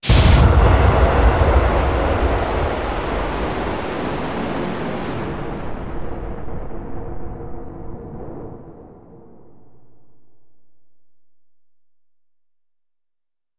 EXPLOSION EXPLOSION 05
Ambient sound effects
EXPLOSION_Explosion_05.mp3